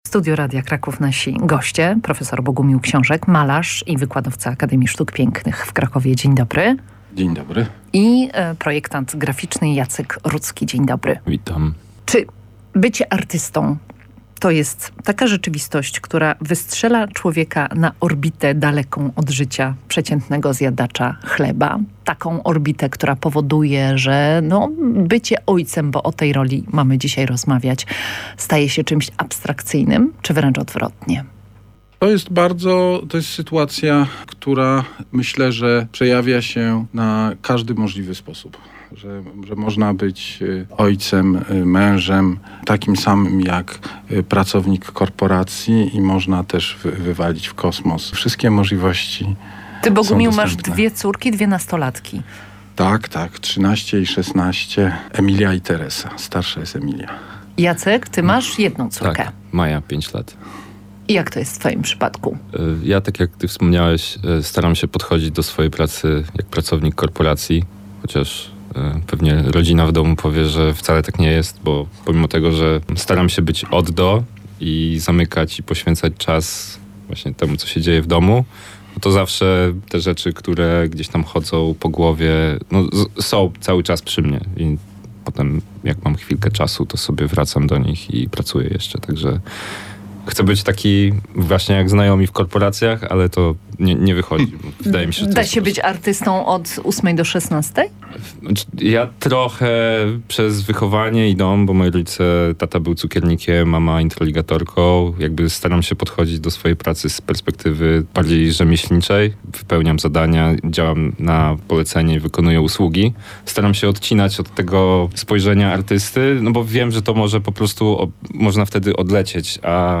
W magazynie "Przed Hejnałem" rozmawiamy o wszystkim, co społecznie ważne, ciekawe, zaskakujące, a czasem bulwersujące.